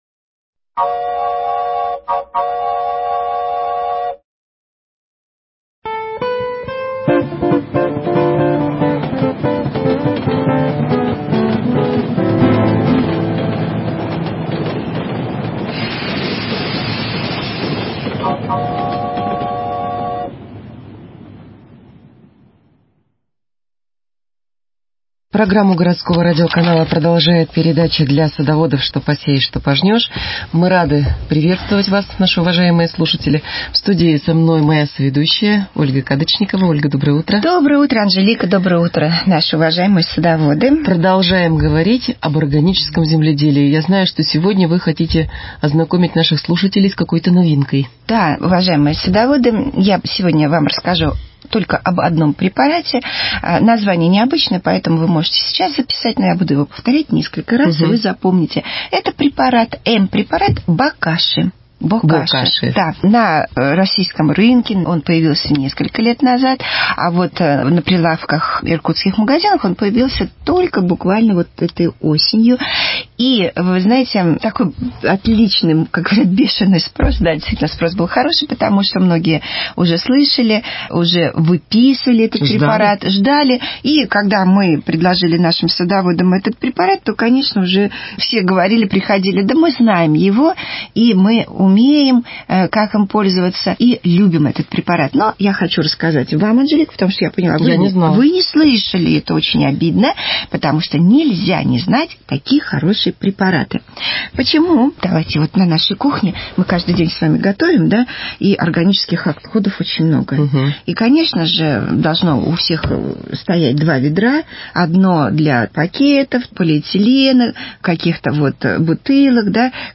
Передача для садоводов и огородников.